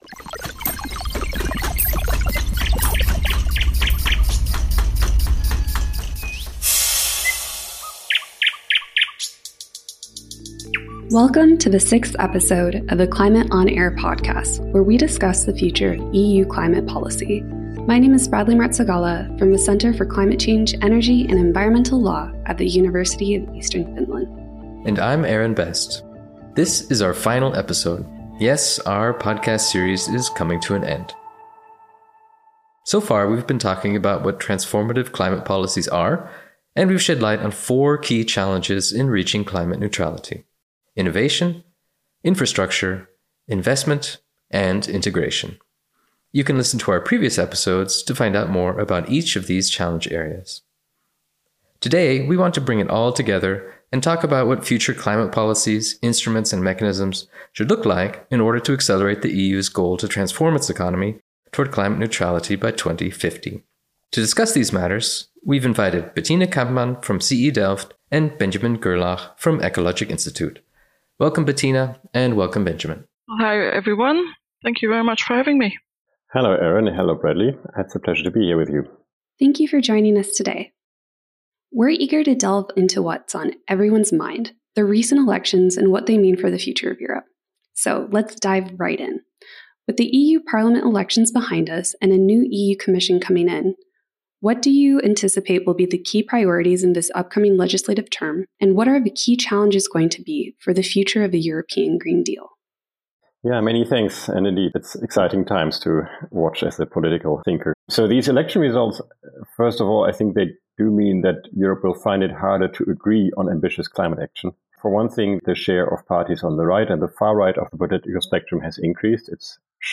Experts from different backgrounds will join in conversation to explore each of them and their relevance for EU policymaking.